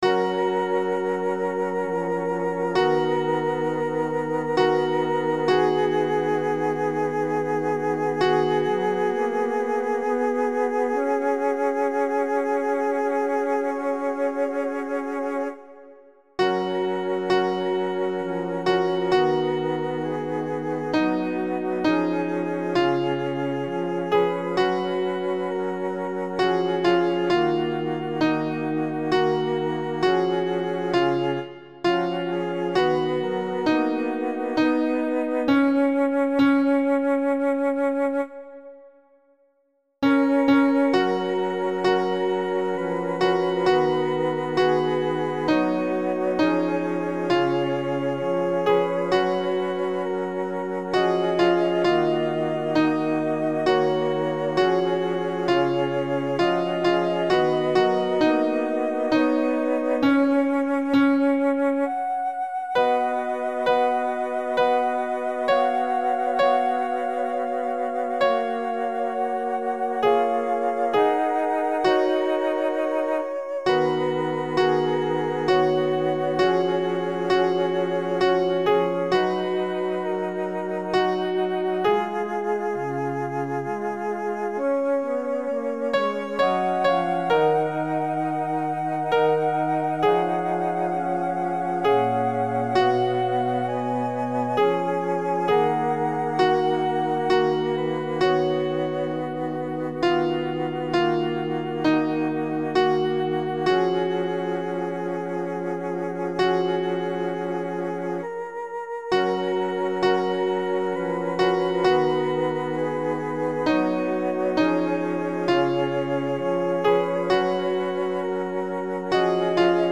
alto 2